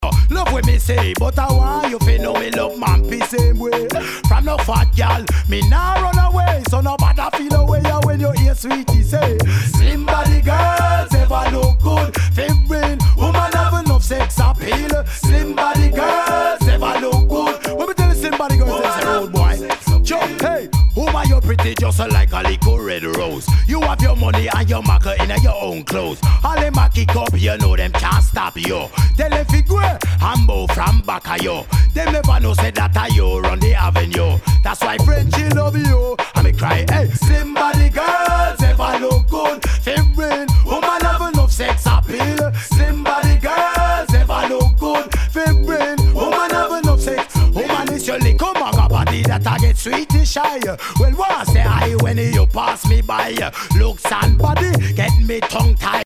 ナイス！ダンスホール！